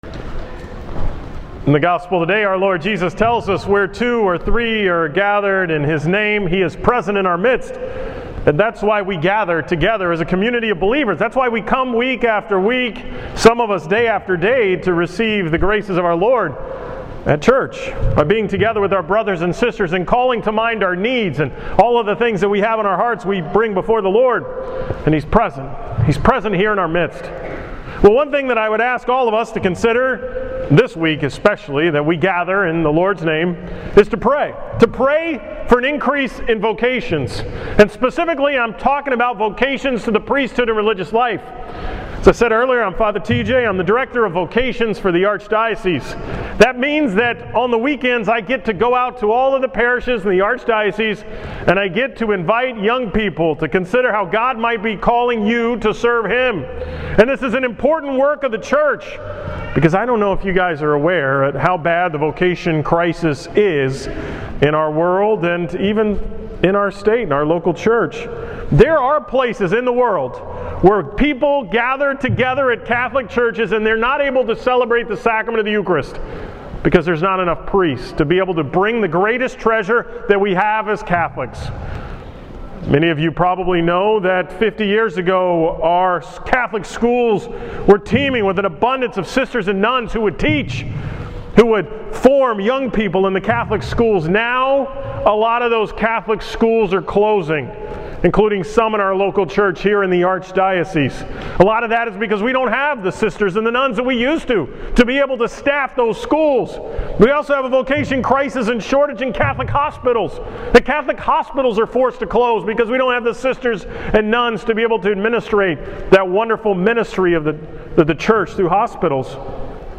From the 11 am Mass at St. Laurence on Sunday, September 7th